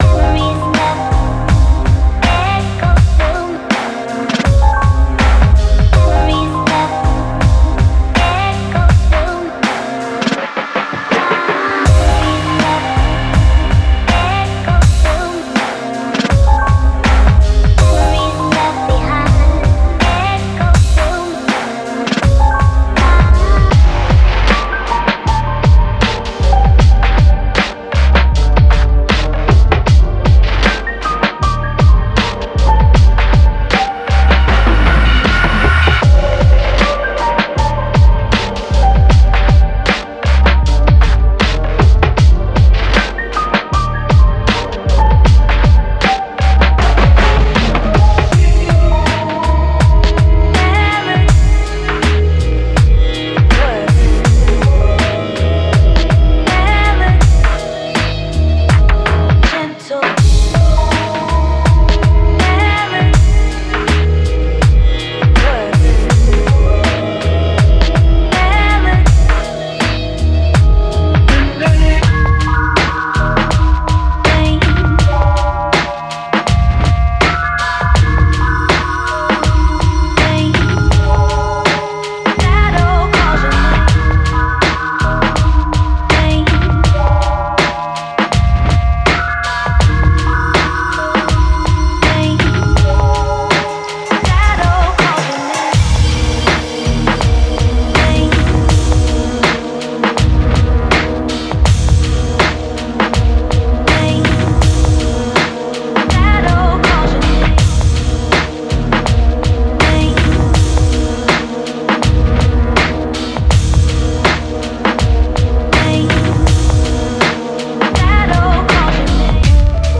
Genre:Hip Hop
デモサウンドはコチラ↓